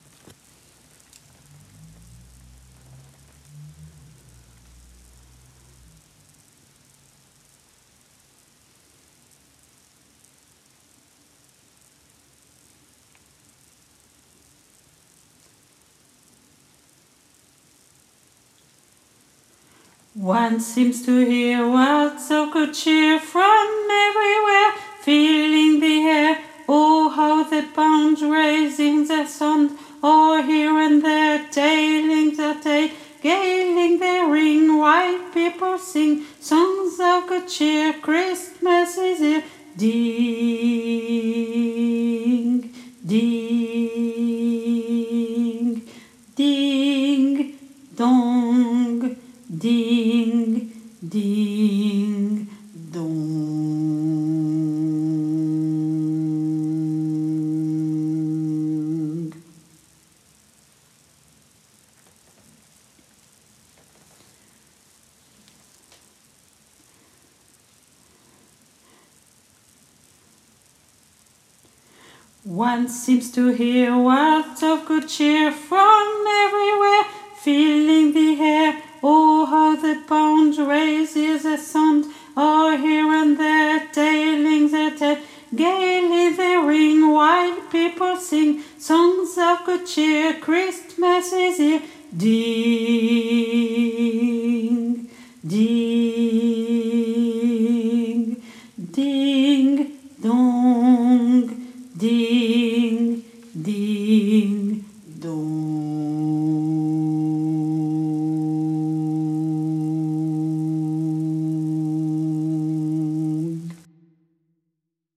MP3 versions chantées
Basse